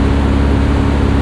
Engines
1 channel